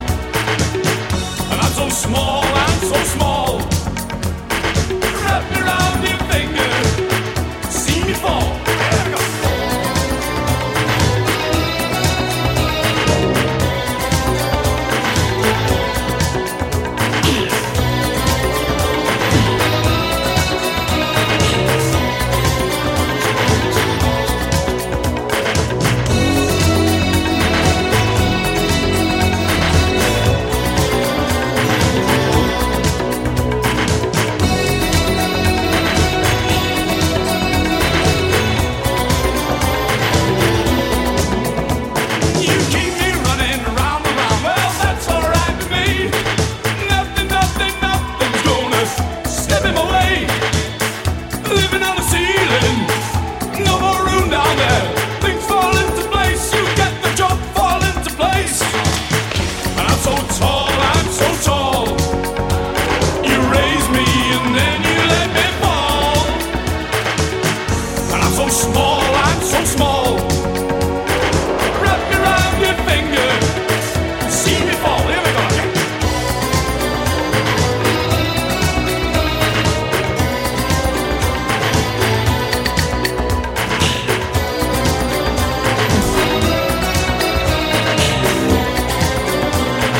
A synth-pop classic